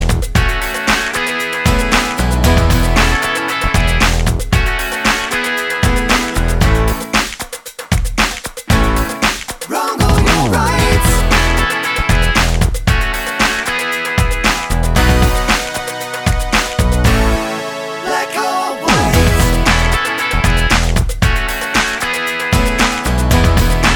No Rapper Pop (1980s) 3:14 Buy £1.50